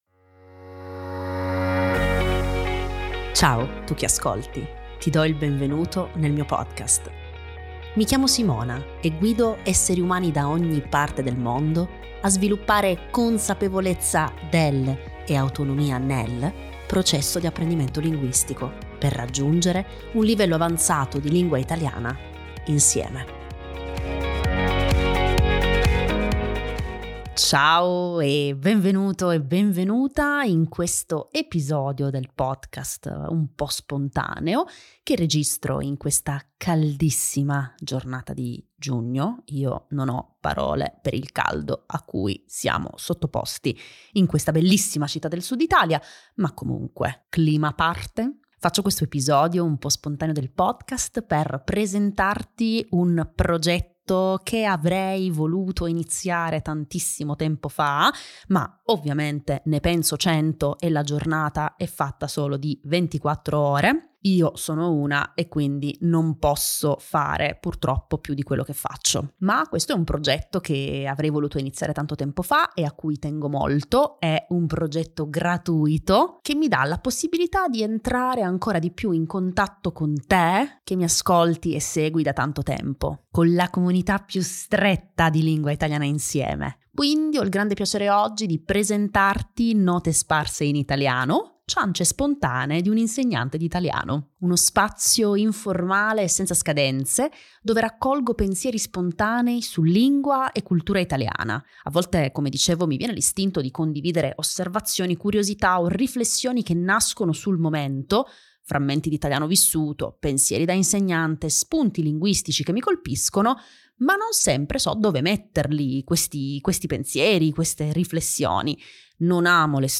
🌱✨💬In questo episodio ti presento il mio nuovo progetto gratuito, “Note sparse in italiano”, un canale Telegram spontaneo dove condivido pensieri, riflessioni e frammenti di lingua e cultura italiana. In questo episodio del podcast ti faccio ascoltare il primo audio condiviso nel canale in cui parlo di due affascinanti tradizioni pugliesi: 💌l’usanza di consegnare a mano gli inviti di matrimonio casa per casa, 🕒il concetto meridionale della controra, il momento sospeso del primo pomeriggio, ricco di significato culturale e simbolico.